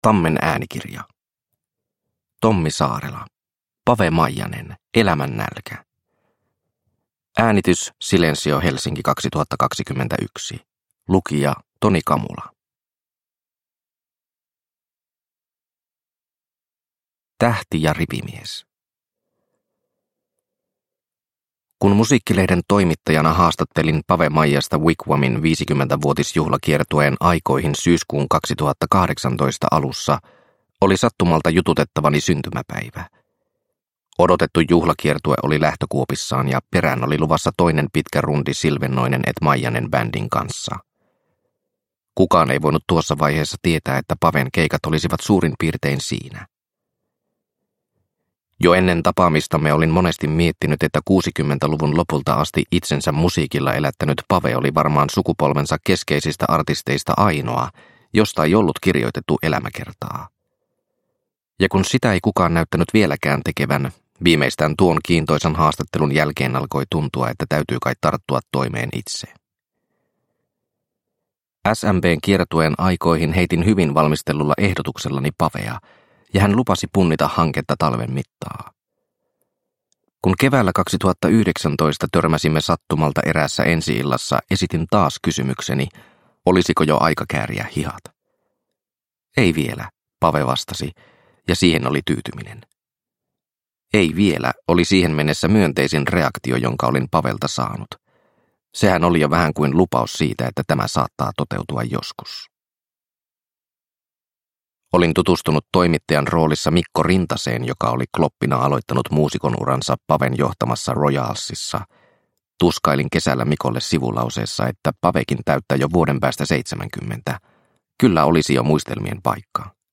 Pave Maijanen - Elämän nälkä – Ljudbok – Laddas ner